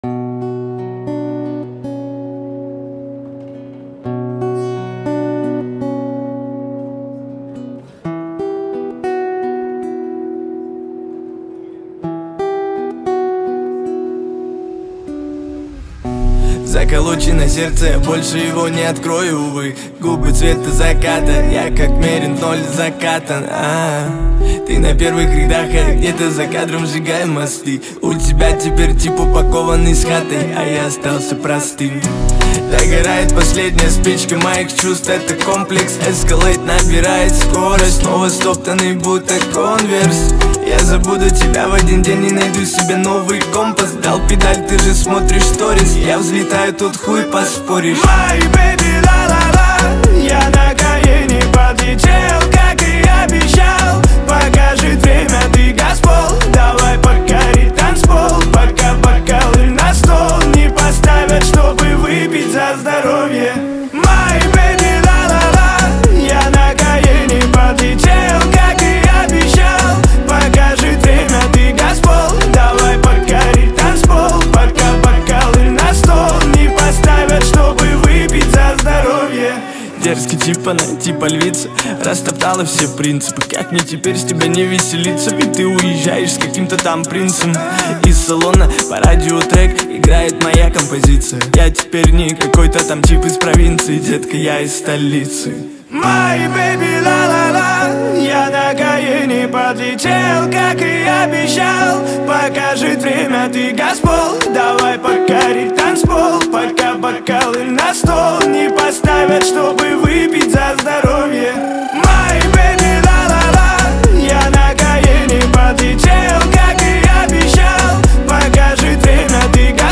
наполнена энергией и оптимизмом